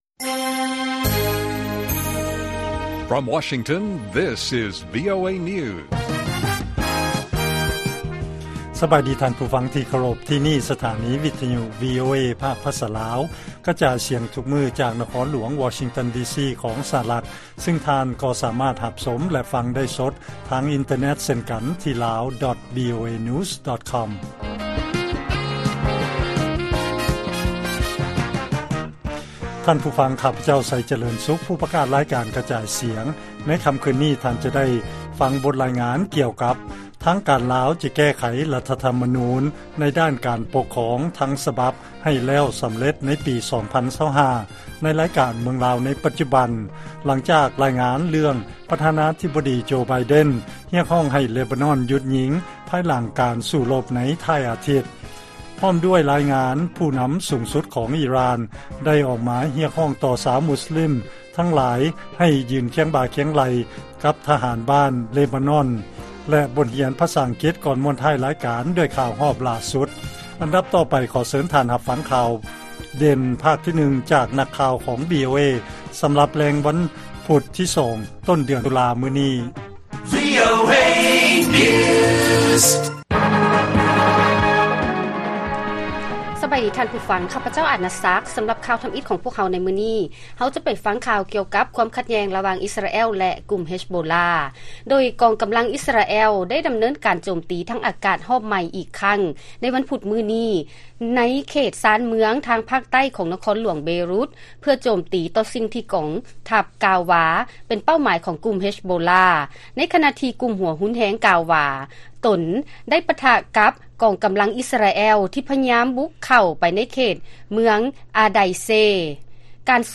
ລາຍການກະຈາຍສຽງຂອງວີໂອເອ ລາວ: ທ່ານ ໄບເດັນ ຮຽກຮ້ອງໃຫ້ມີການຢຸດຍິງໃນເລບານອນ ລຸນຫຼັງມີການສູ້ລົບກັນໃນທ້າຍອາທິດທີ່ຜ່ານມາ.